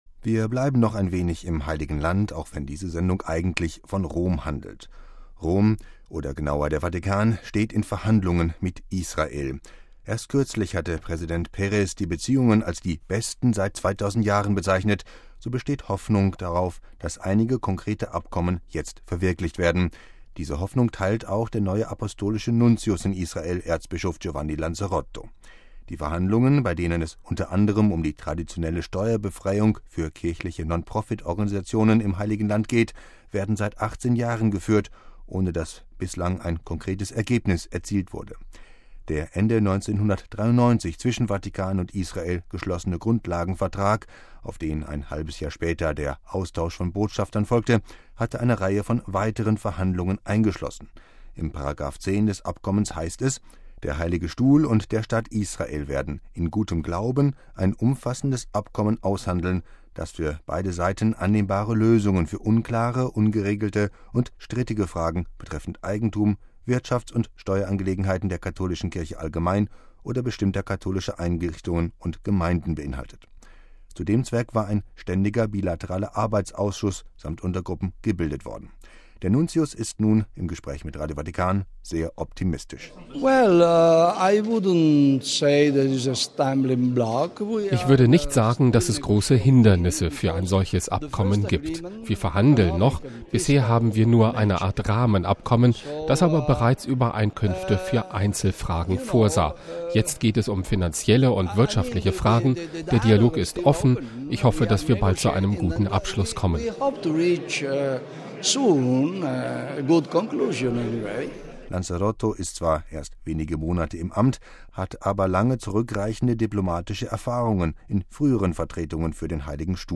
Das sagte der neue apostolische Nuntius in Israel, Erzbischof Giuseppe Lanzarotto, im Interview mit Radio Vatikan. Die Verhandlungen, bei denen es unter anderem um die traditionelle Steuerbefreiung für kirchliche Nonprofit-Organisationen im Heiligen Land geht, werden seit 18 Jahren geführt, ohne dass bislang ein konkretes Ergebnis erzielt wurde.